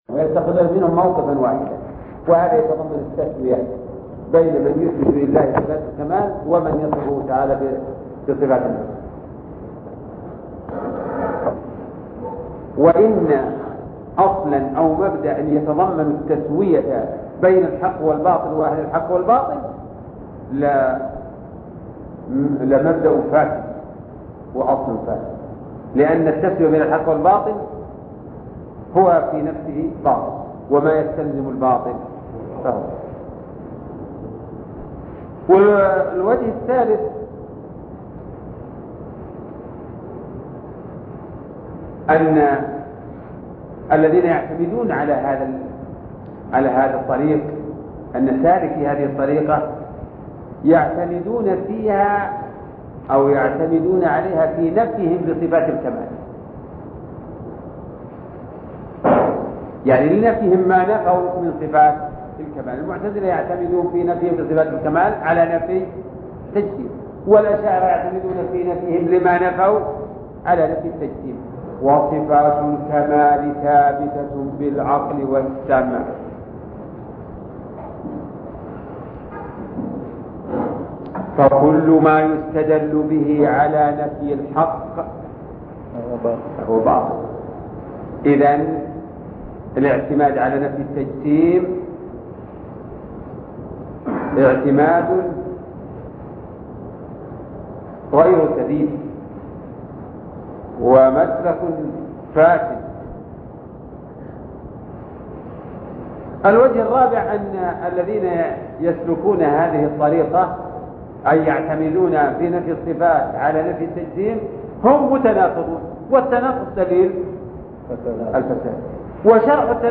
شرح الرسالة التدمرية (32) الدرس الثاني والثلاثون - الشيخ عبد الرحمن بن ناصر البراك